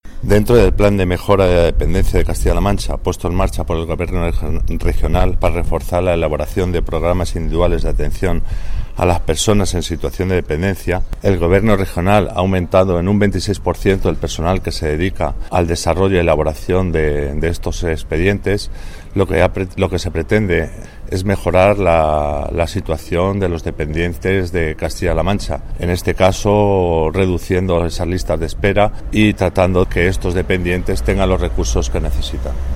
El director provincial de Bienestar Social en Guadalajara, José Luis Vega, habla de la mejora en la prestación del servicio de atención a la dependencia.